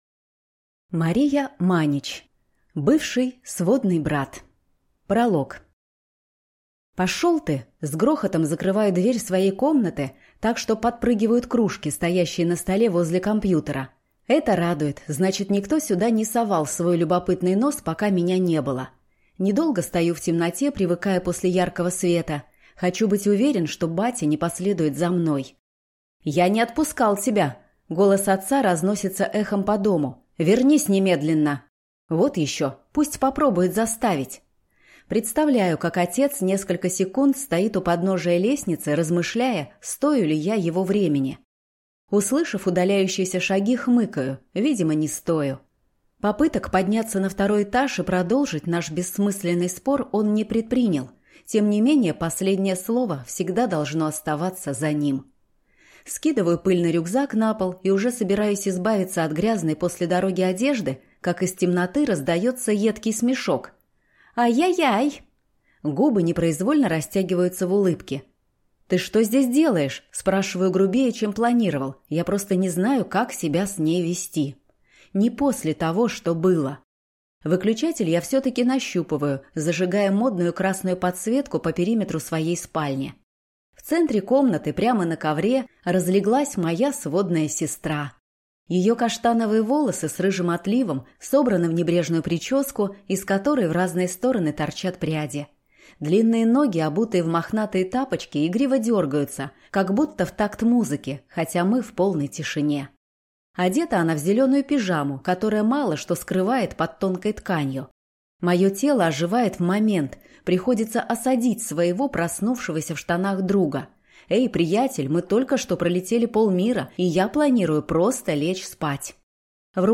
Аудиокнига Бывший сводный брат | Библиотека аудиокниг